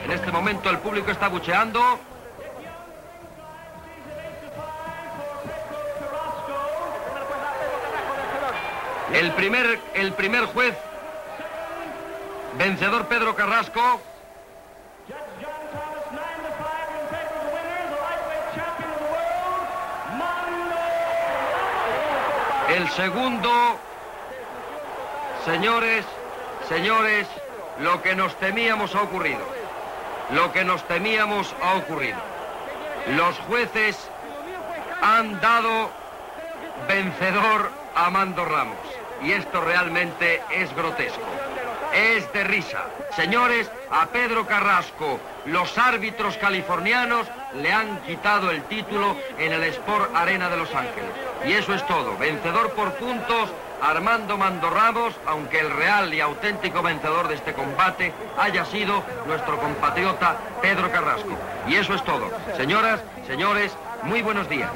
Transmissió del combat de boxa entre Pedro Carrasco i Armando "Mando" Ramos a Los Ángeles (EE.UU.). Votacions dels jutges del combat i resultat final.
Esportiu